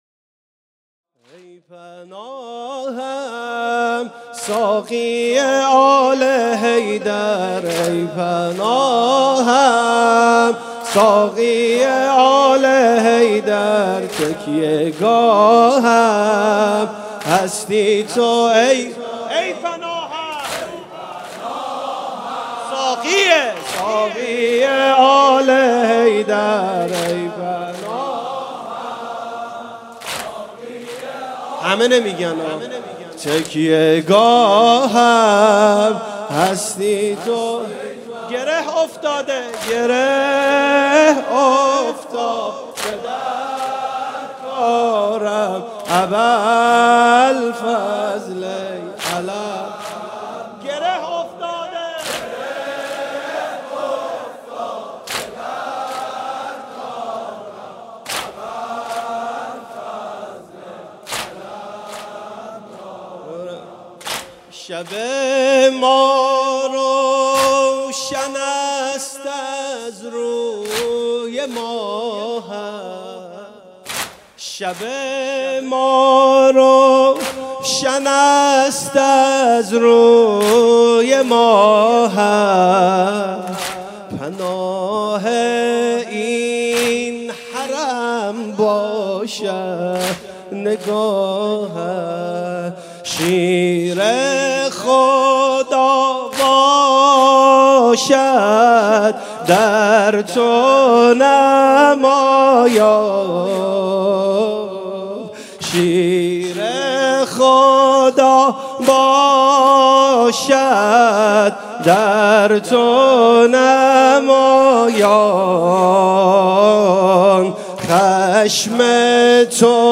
شب هفتم محرم 97 - هیئت شبان القاسم - گره افتاده در کارم